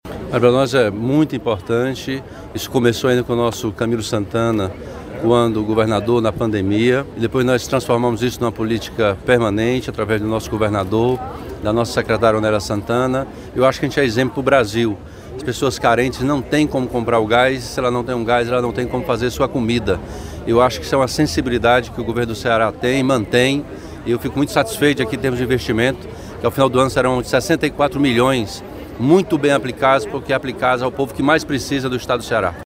Para o governador Elmano de Freitas a política do Vale-Gás é uma referência para o Brasil. Ele fala dos critérios para escolher as famílias que vão receber o benefício.
16.03-ELMANO-1-GAS.mp3